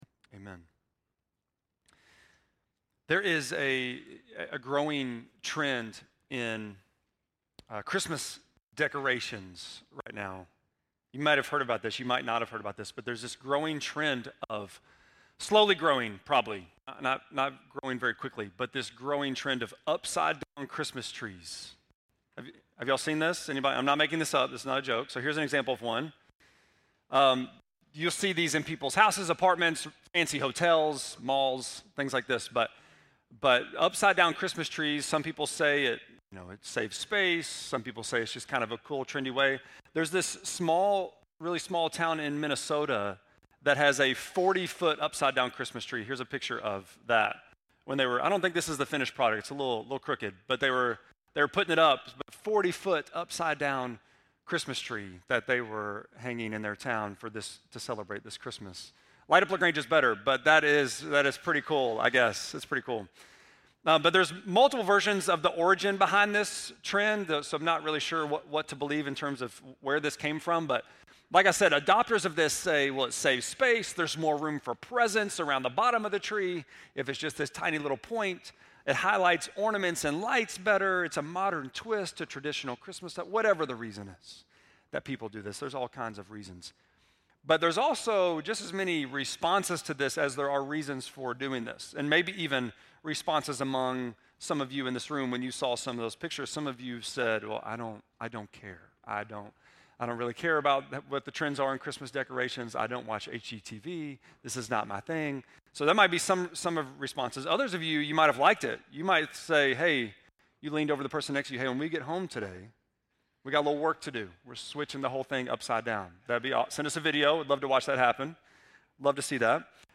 12.8-sermon.mp3